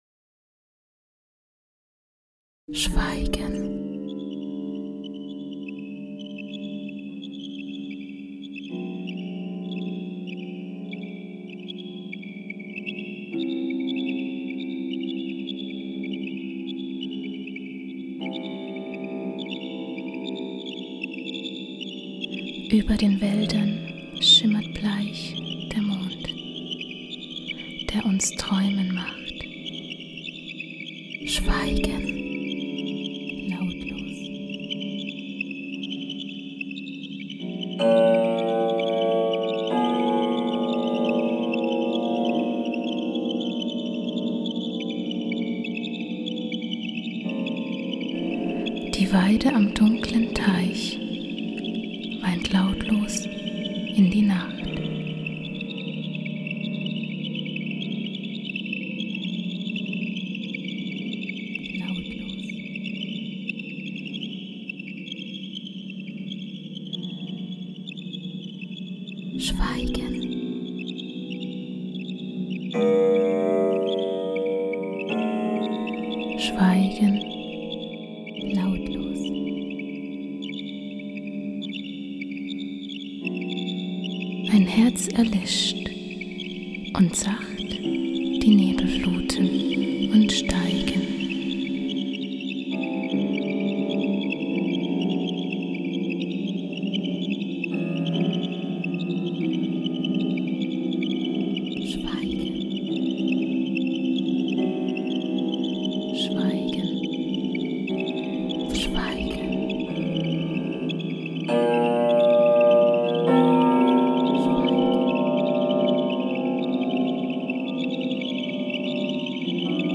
Meine Aufgabe bestand darin, die Gedichte auf verschiedenste Art und Weise einzusprechen.